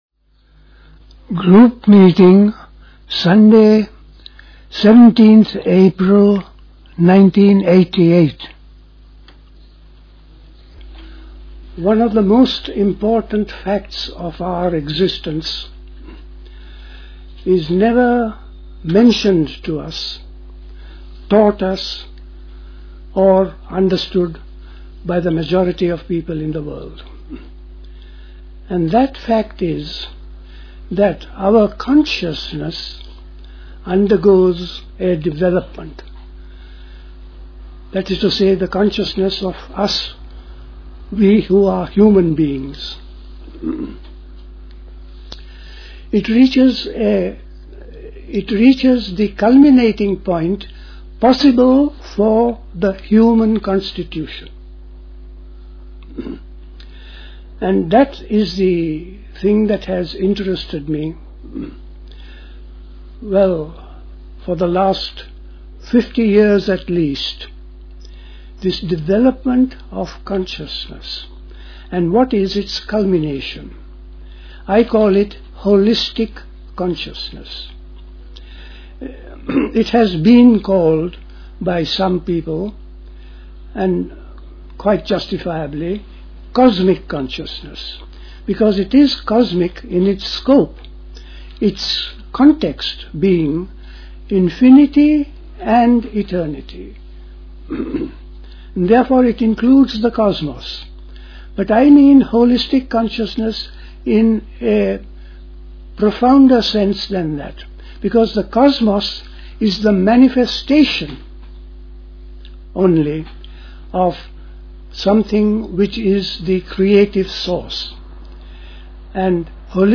The context of Holistic Consciousness is Infinity and Eternity. The Epilogue of Holistic Consciousness read.